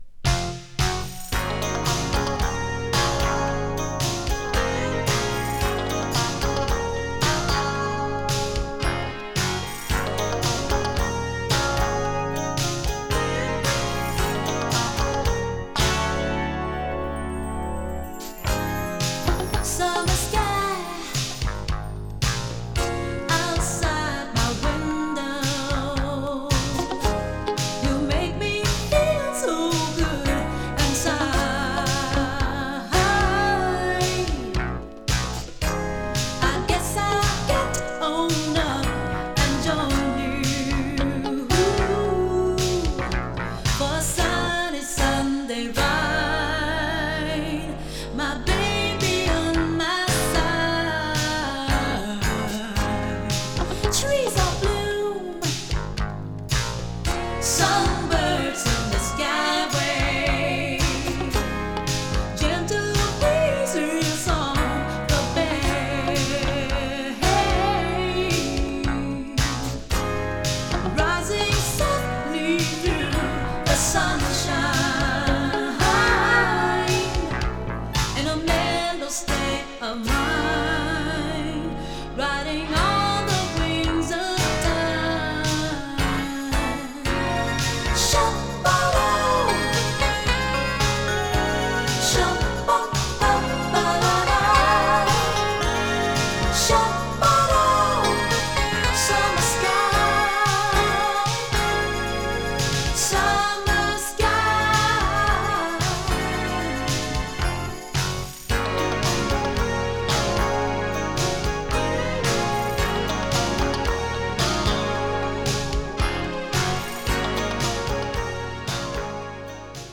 数多くのフュージョン作品に参加するカリフォルニア出身のパーカッション奏者。
女性ヴォーカルをフューチャーしたメロウ&アーバンな
シンセサイザーを多用したエレクトリックなフュージョン～ファンク・ナンバーを収録！
【FUSION】